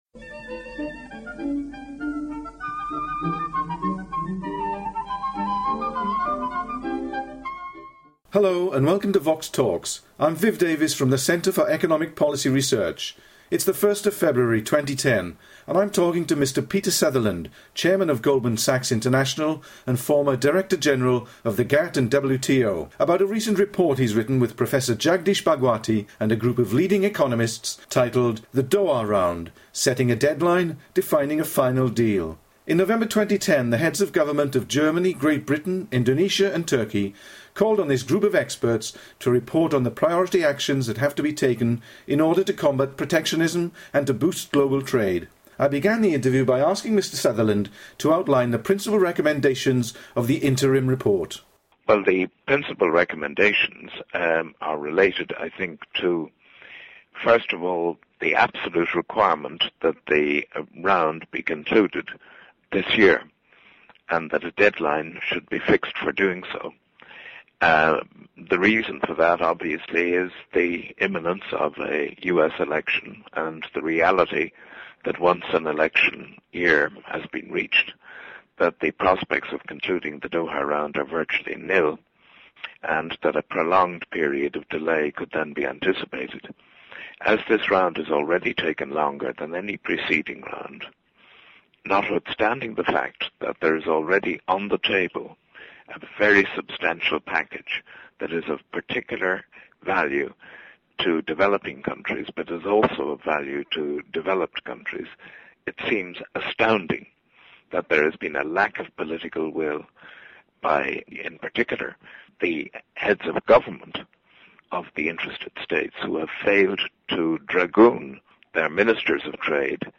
He explains why Doha has stalled and presents the case for its immediate completion. He maintains it is crucial that governments now commit to concluding Doha by the end of 2011 or else the round is doomed and all that has been achieved will be lost, with disastrous consequences for world trade. The interview was recorded by telephone on 1 February 2011.